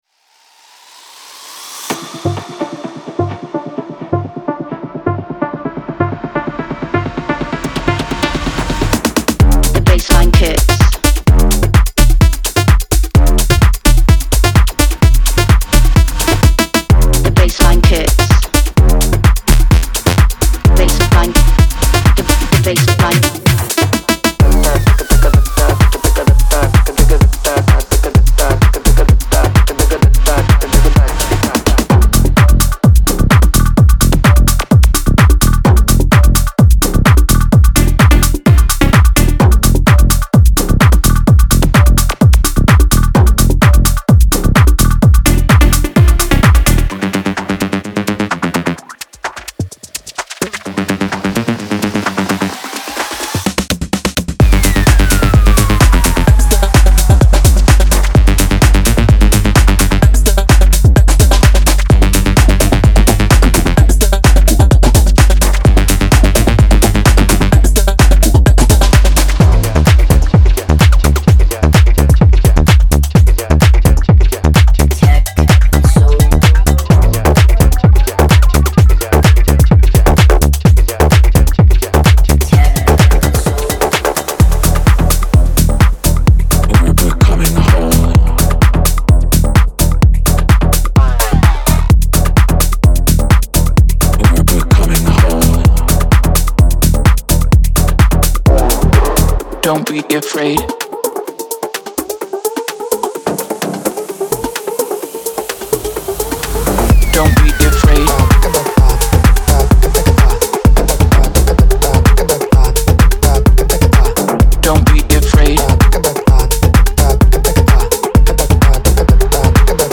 Genre:Tech House
デモサウンドはコチラ↓
128 BPM
30 Bass Loops
60 Percussion Loops
60 Synth Loops
21 Vocal Loops